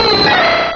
Cri de Debugant dans Pokémon Rubis et Saphir.